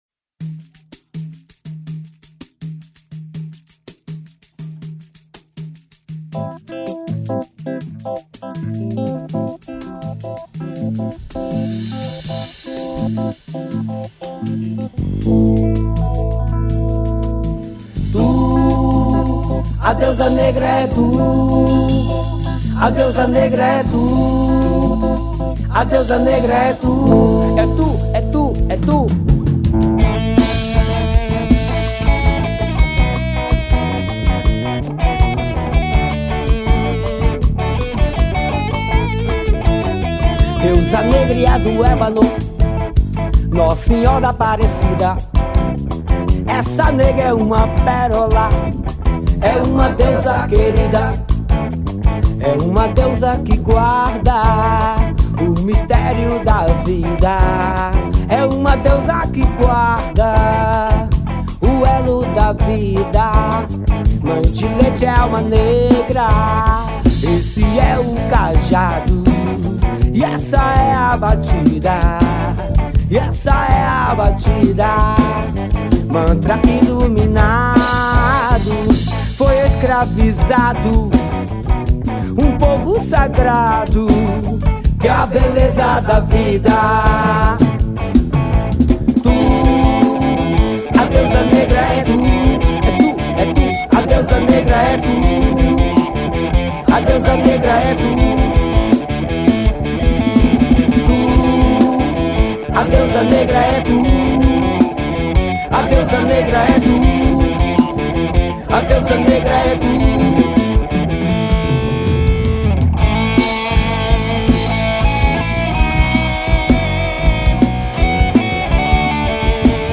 2233   04:01:00   Faixa:     Forró